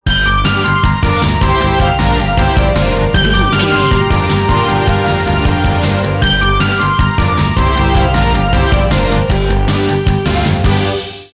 Aeolian/Minor
Fast
chaotic
driving
energetic
bass guitar
synthesiser
percussion
electric piano